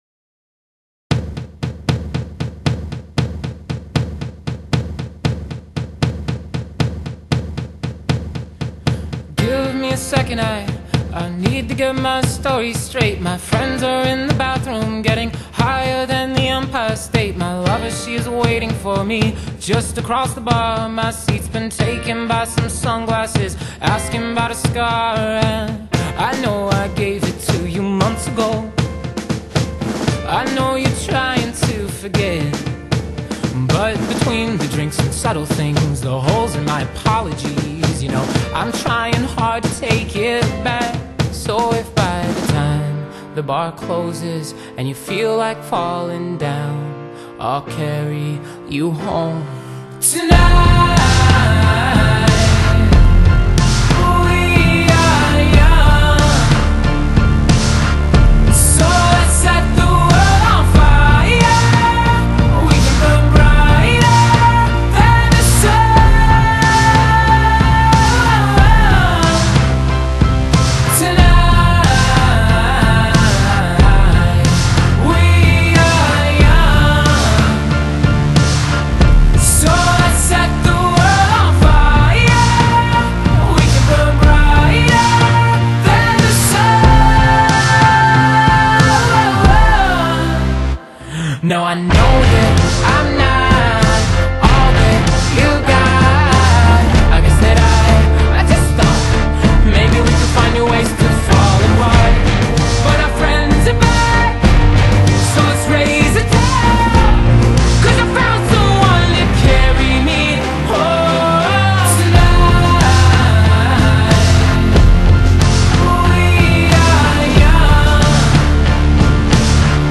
Genre: Pop, R&B, Pop Rock